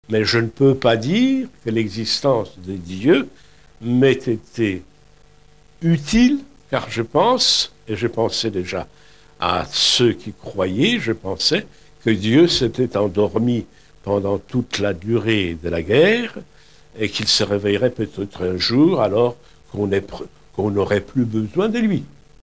Témoignages de survivants.